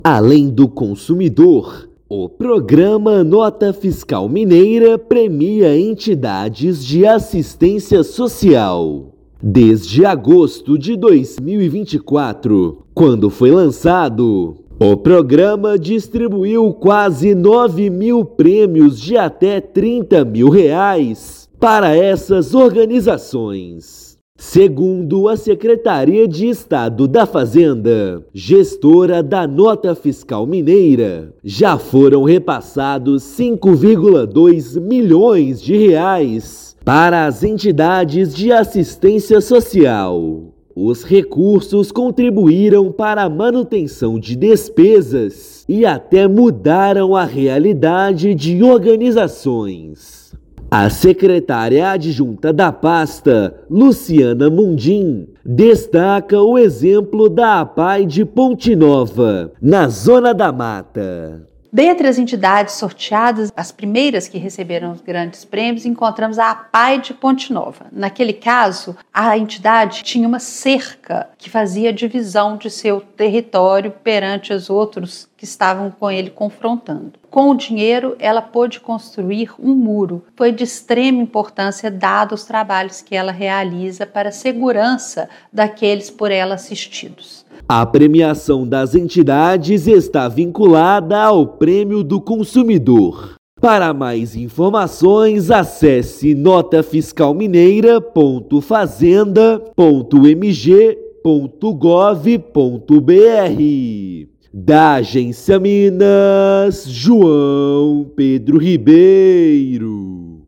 Desde o início do programa, em 2024, foram entregues 8,8 mil prêmios e destinados R$ 5,2 milhões às organizações beneficentes. Ouça matéria de rádio.